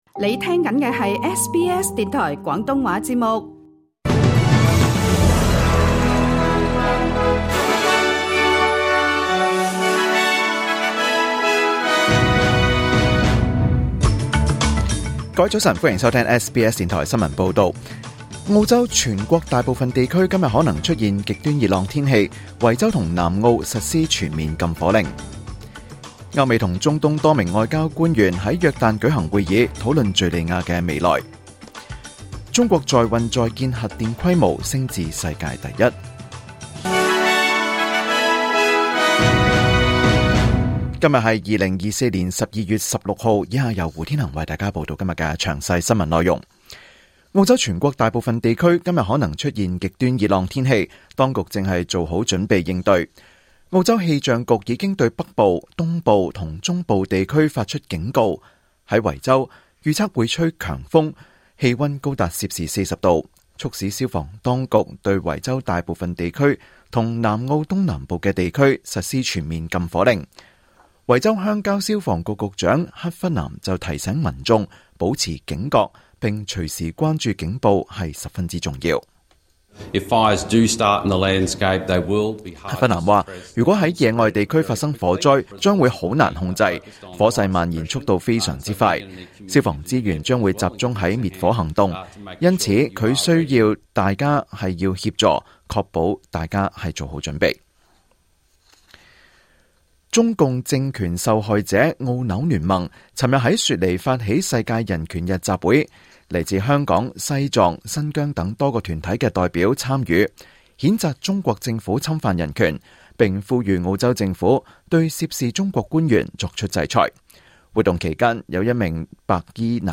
2024年12月16日SBS廣東話節目詳盡早晨新聞報道。